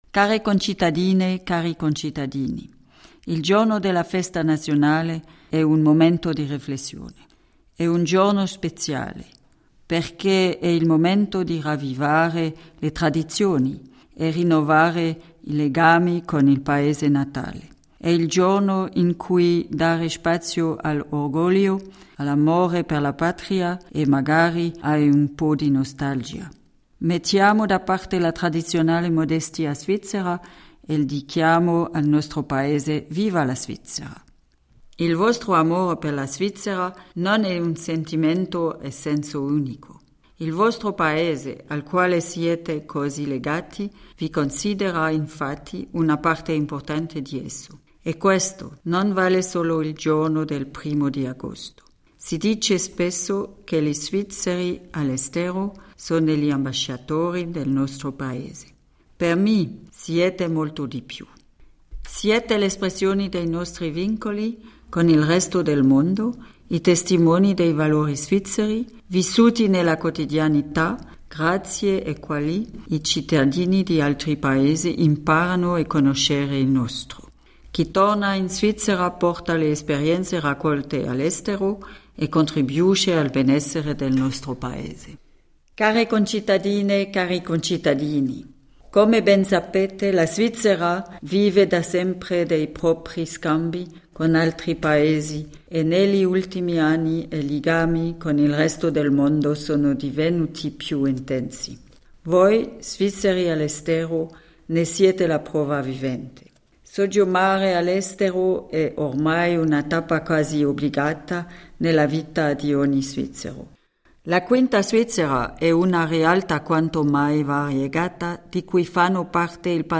Messaggio della presidente della Confederazione Micheline Calmy-Rey agli Svizzeri all’estero in occasione della Festa nazionale